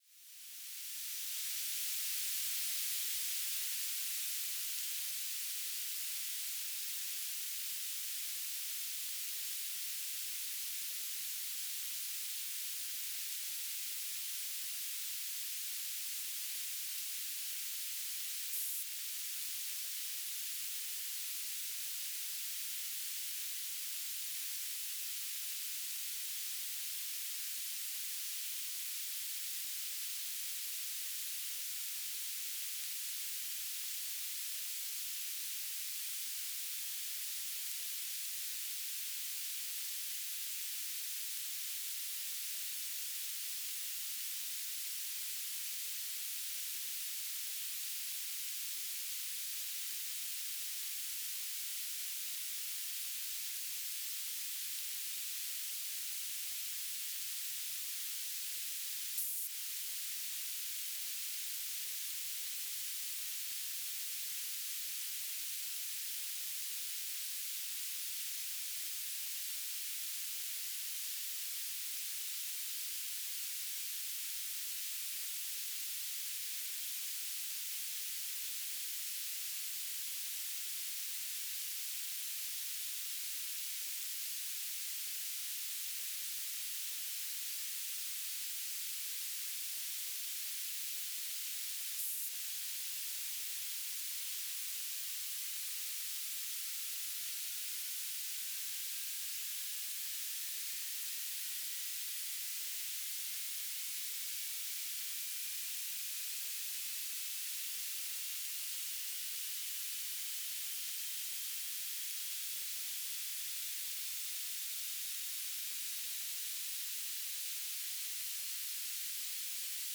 "transmitter_description": "Mode U - BPSK9k6 G3RUH",
"transmitter_mode": "BPSK",